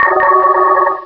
Cri de Rosabyss dans Pokémon Rubis et Saphir.